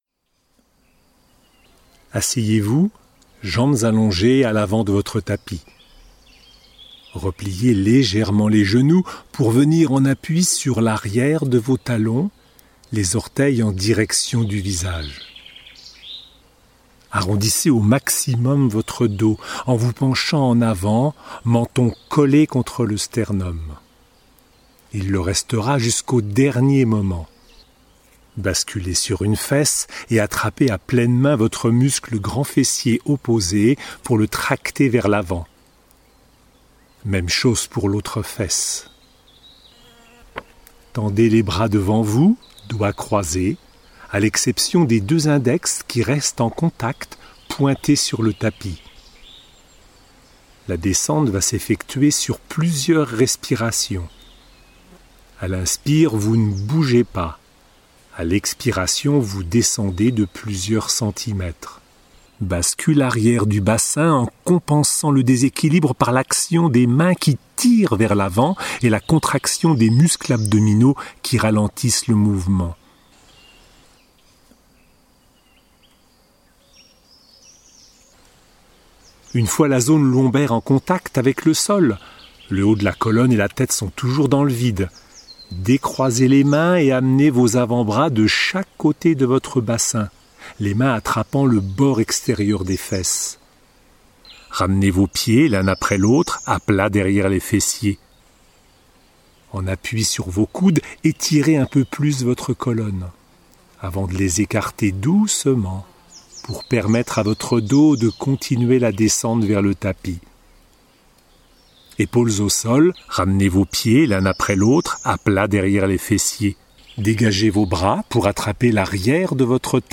N.B. : Pour plus de tranquillité, je suis allé enregistrer en lisière de forêt (attestation de sortie en poche bien entendu !) : les bruits des oiseaux et autres insectes que vous entendez en arrière-plan sont réels...